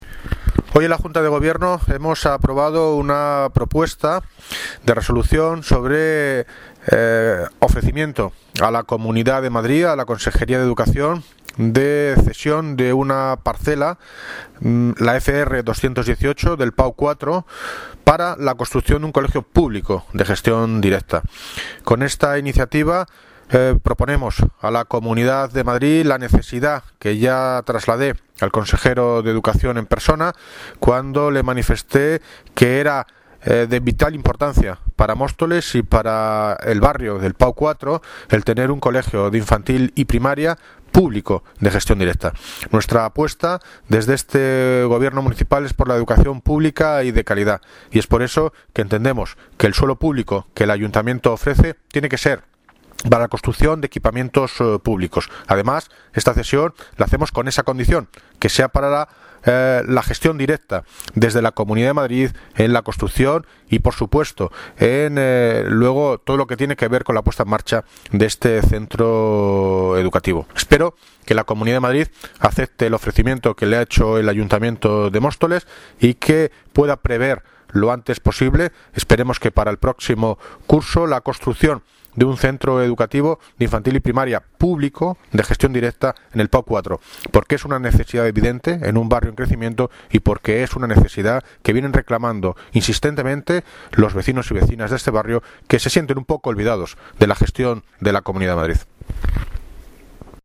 Audio - David Lucas (Alcalde de Móstoles) Sobre ofrecimiento Parcela para Colegio